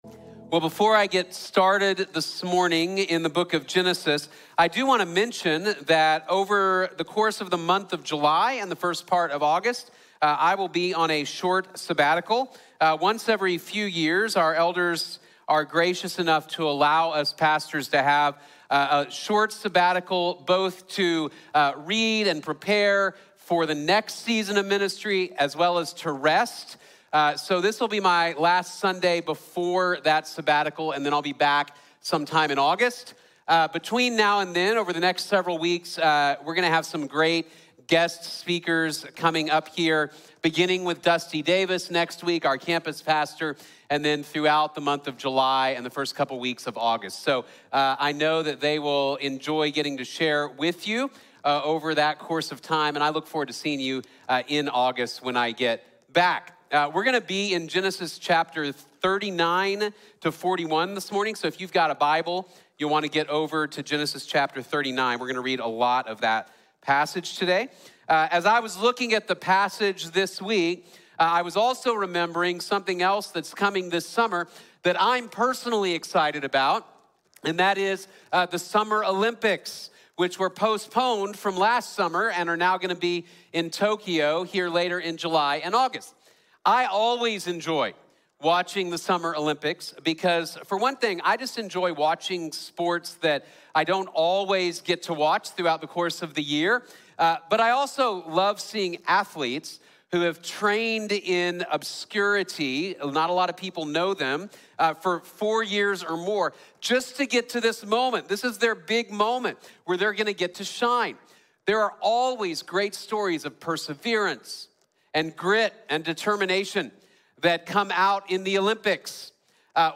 Don’t Give Up | Sermon | Grace Bible Church